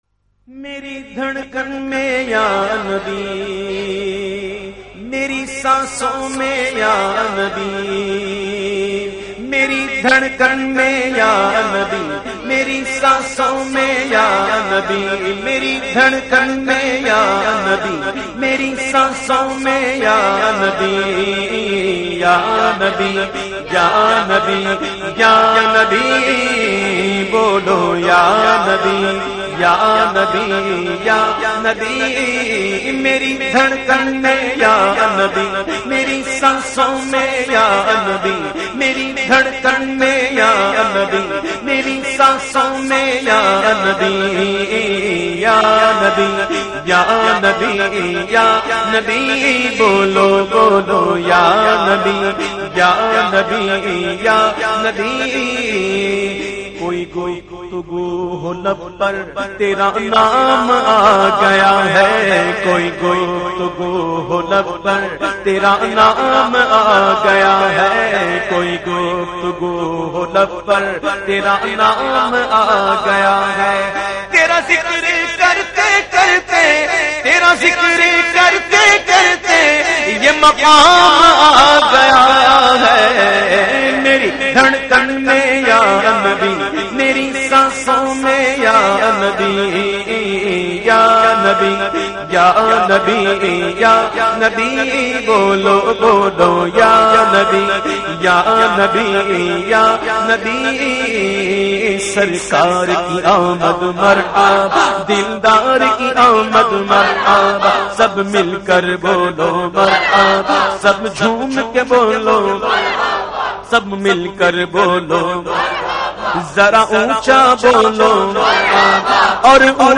The Naat Sharif meri dharkan mein ya nabi recited by famous Naat Khawan of Pakistan Owais Raza Qadri.